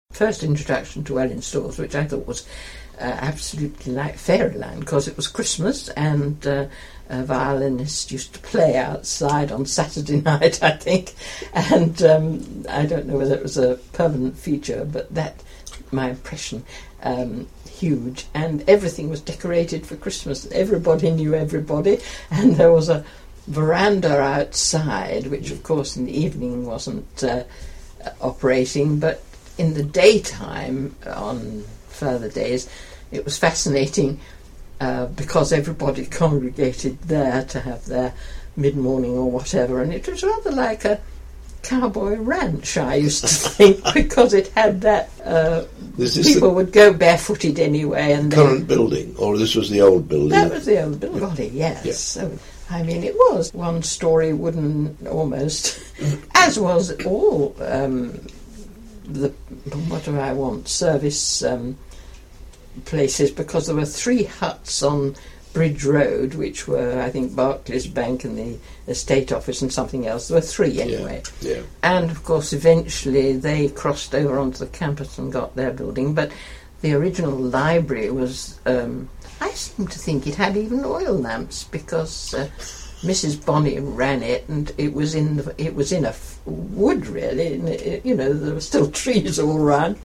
Memories - early residents contributed their recollections of the town which were digitally recorded as oral histories by a team of volunteers.